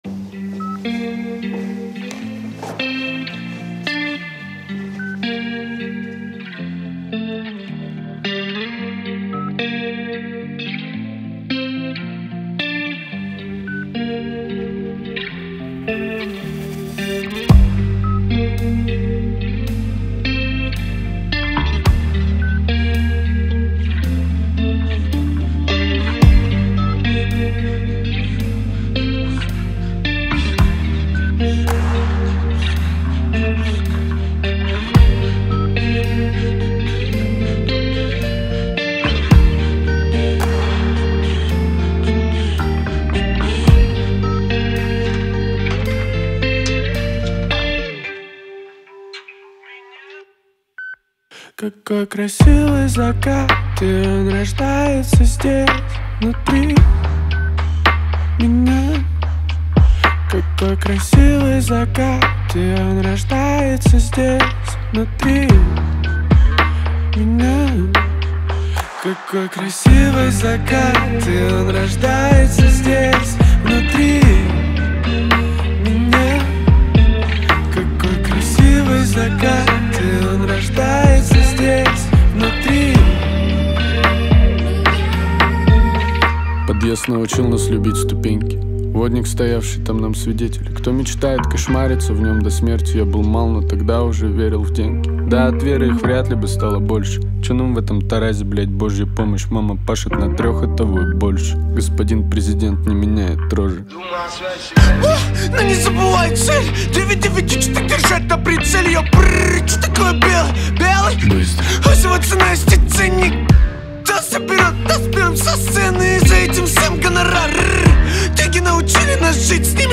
погружает слушателя в атмосферу меланхолии